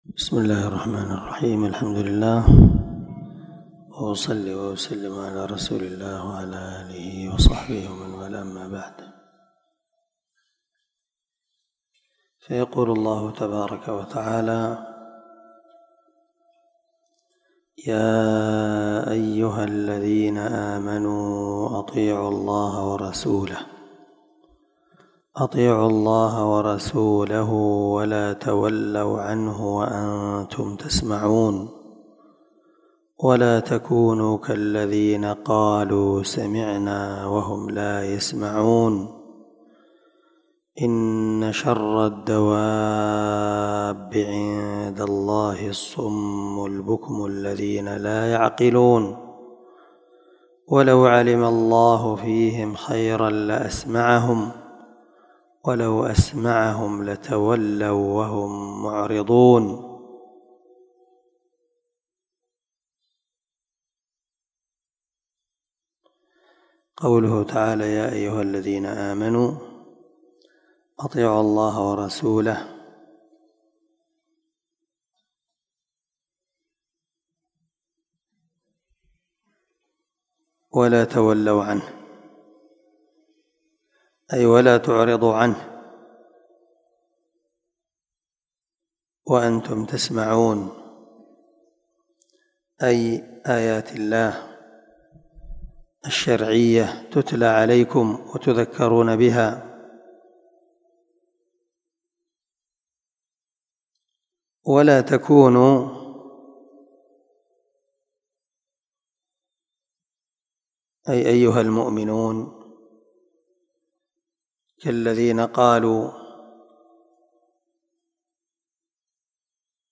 510الدرس7 تفسير آية ( 20 - 23 ) من سورة الأنفال من تفسير القران الكريم مع قراءة لتفسير السعدي